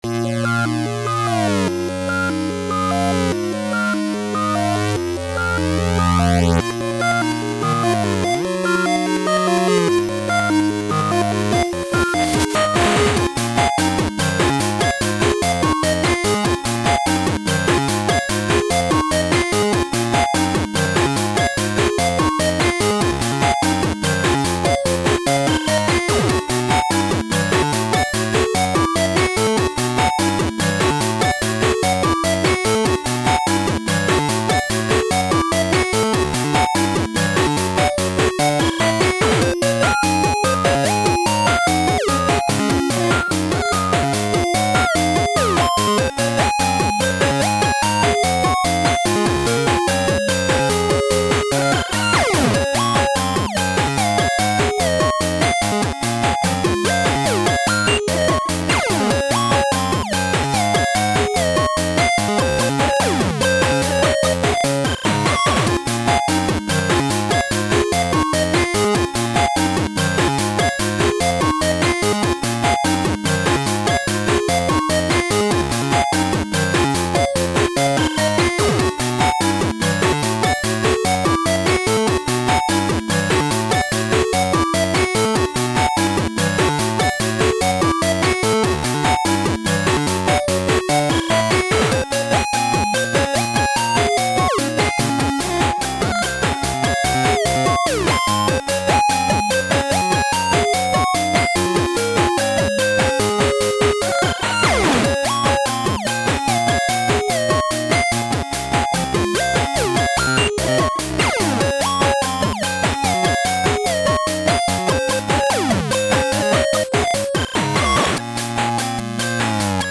[LSDj]